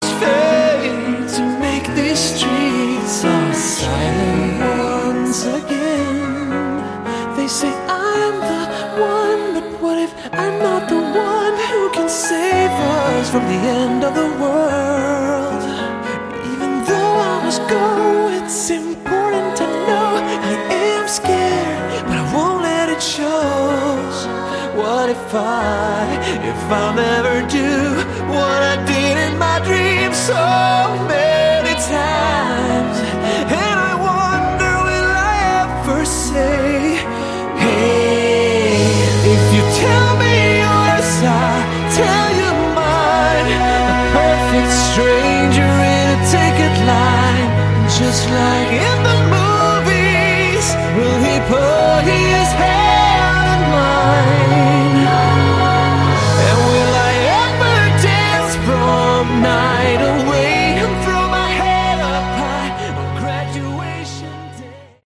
Category: Prog Rock / Melodic Metal
bass
guitar
vocals
keyboards
drums